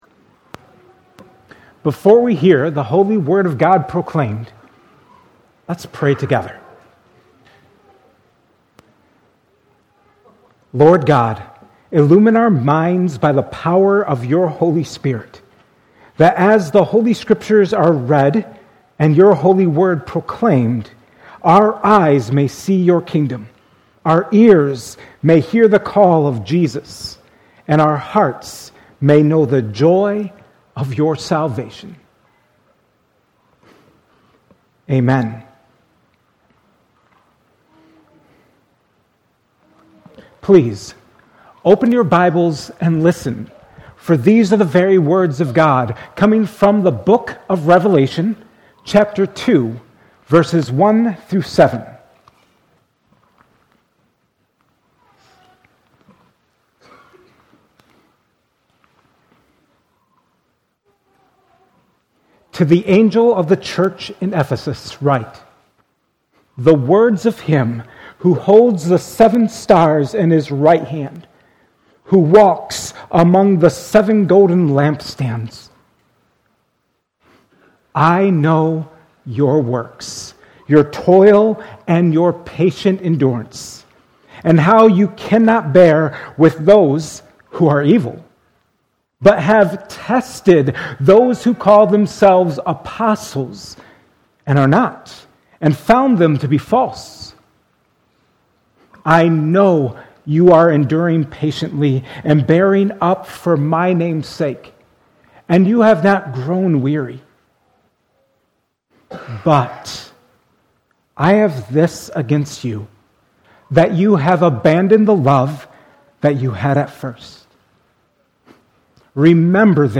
Sermon audio given each Sunday at Cornerstone Church in Pella, IA.
Sermons from Cornerstone Church